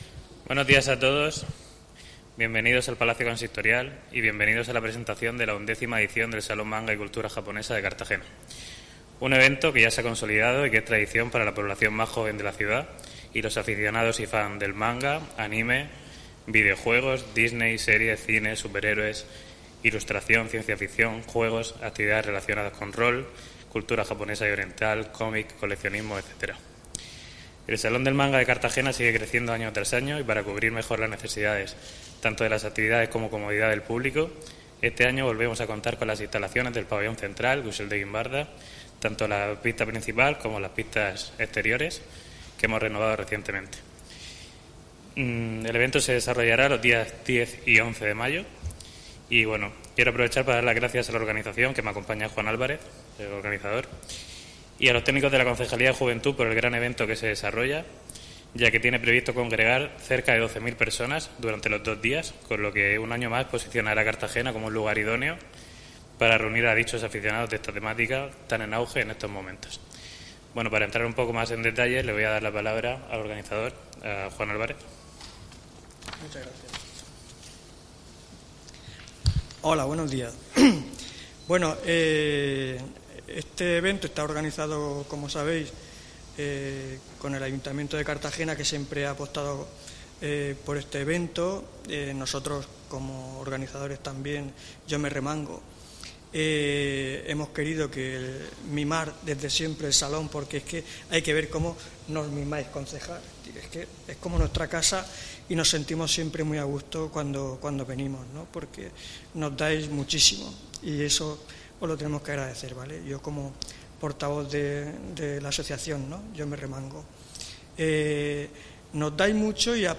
Audio: Presentaci�n del XI Sal�n del Manga de Cartagena (MP3 - 6,73 MB)